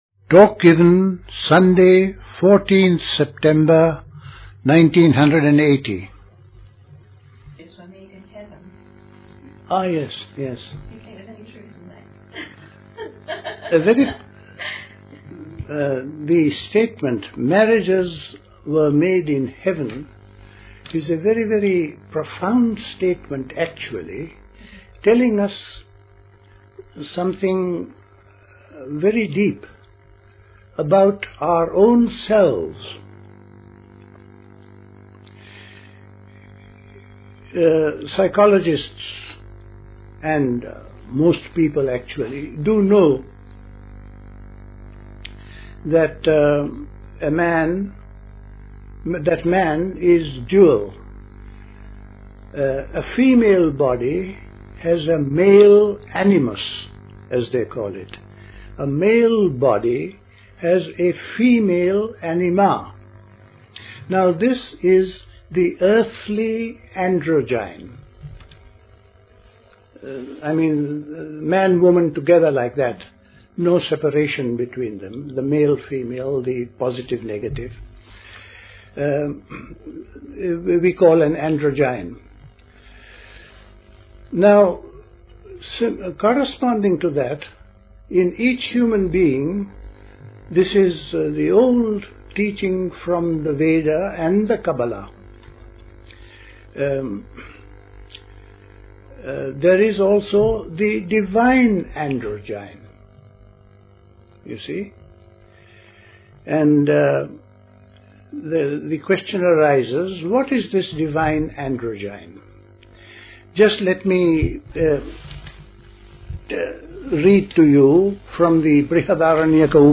A talk
at Dilkusha, Forest Hill, London on 14th September 1980